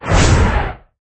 132-Wind01.mp3